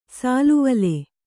♪ sāluvale